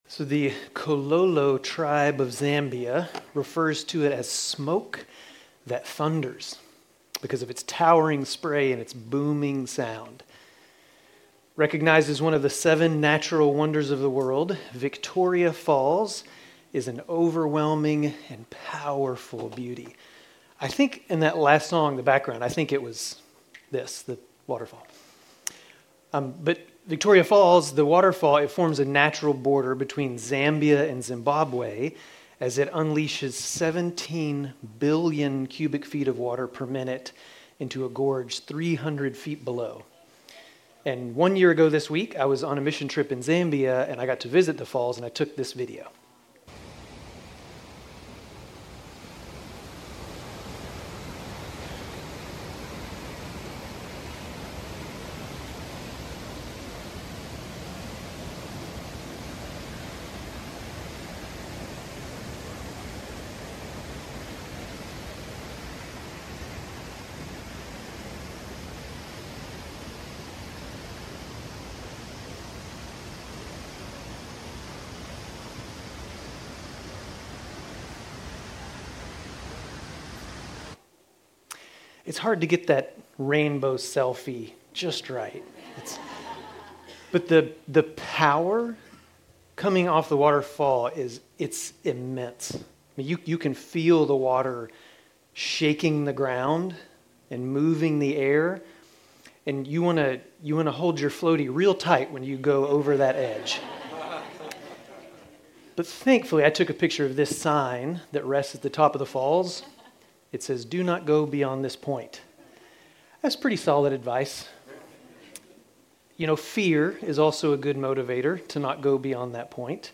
Grace Community Church Dover Campus Sermons 4_27 Dover Campus Apr 27 2025 | 00:25:11 1x 00:00 / 00:25:11 Subscribe Share RSS Feed Share Link Embed